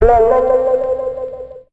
Index of /90_sSampleCDs/Zero-G - Total Drum Bass/Instruments - 2/track39 (Guitars)
01 Mystery Man Bb.wav